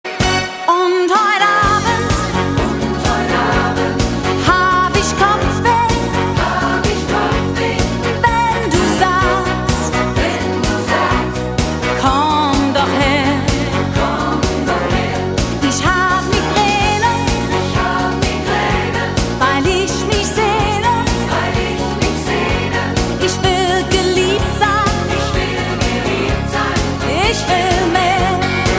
B Besetzung: Blasorchester PDF
Tonart: Es-Dur Artikel-Nr.